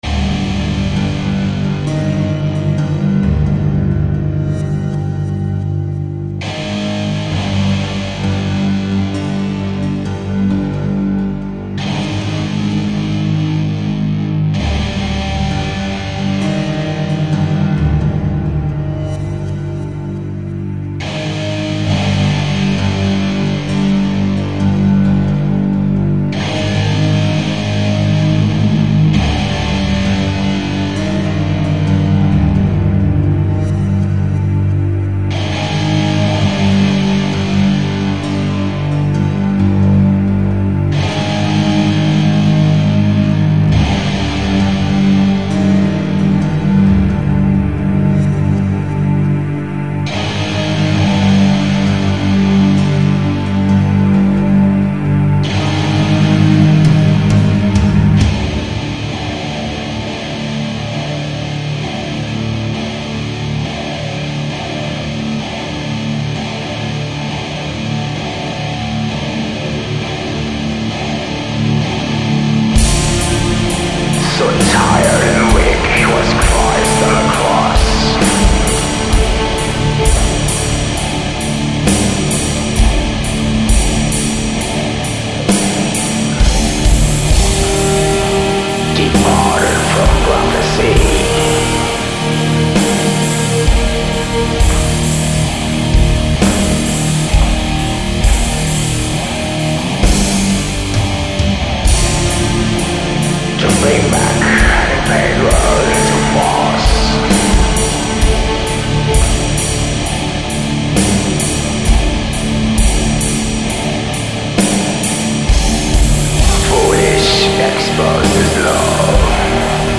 *Genre: Atmospheric Funeral Doom Metal